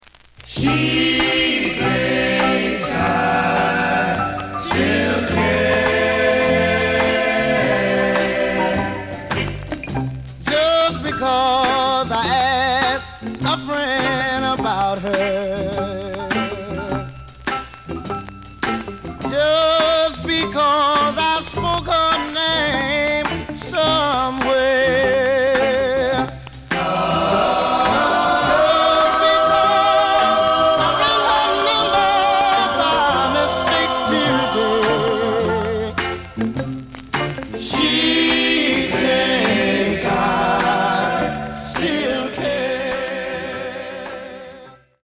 The audio sample is recorded from the actual item.
●Genre: Rhythm And Blues / Rock 'n' Roll
Noticeable noise on both sides due to scratches.)